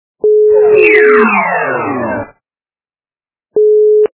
» Звуки » другие » Звук - Лезера
При прослушивании Звук - Лезера качество понижено и присутствуют гудки.